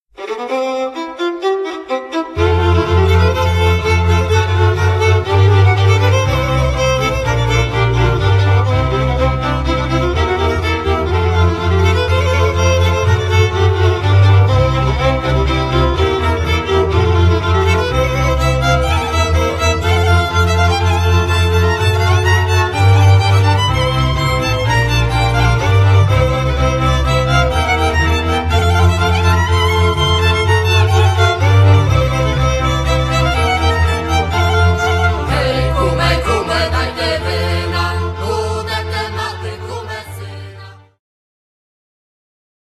skrzypce, altówki, ¶piew solo violin, violas, solo vocals
kontrabas doublebass